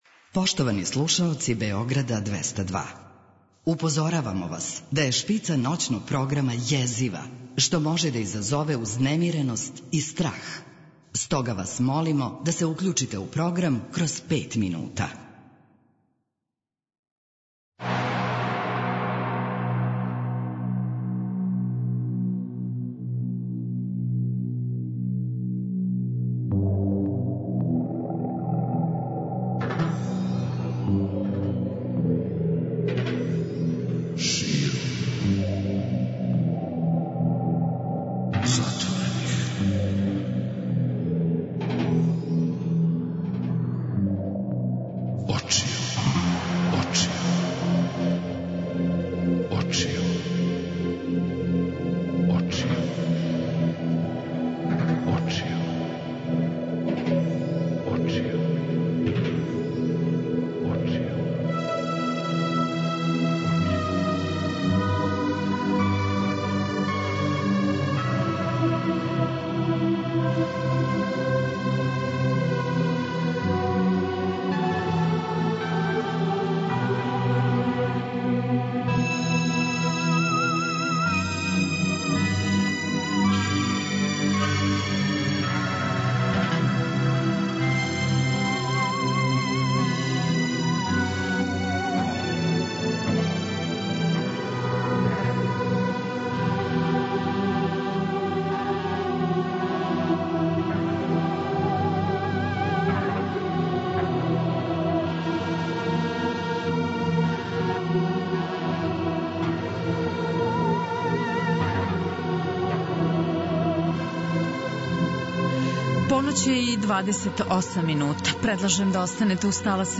Гост Ноћног програма Широм затворених очију је Ђорђе Давид, музичар и глумац.